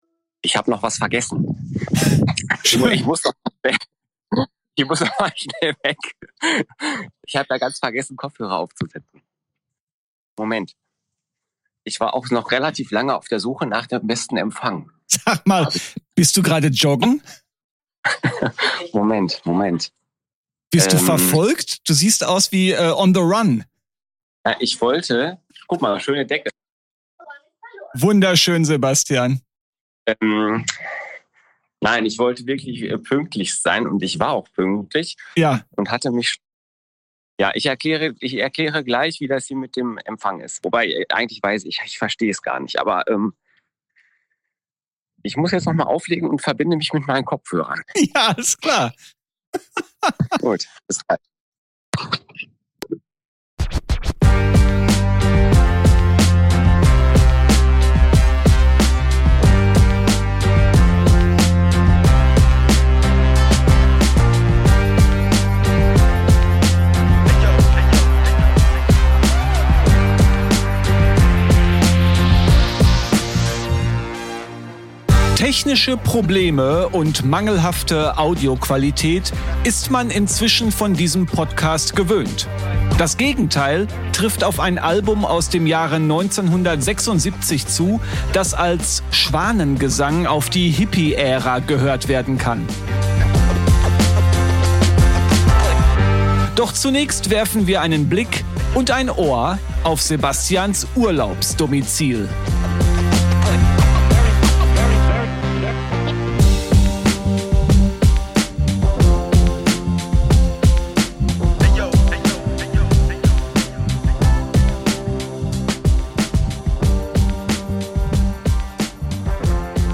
Technische Probleme und mangelhafte Audioqualität ist man inzwischen von diesem Podcast gewöhnt. Das Gegenteil trifft auf ein Album aus dem Jahre 1976 zu, das als Schwanengesang auf die Hippie-Ära gehört werden kann.